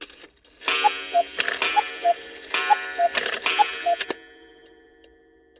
cuckoo-clock-04.wav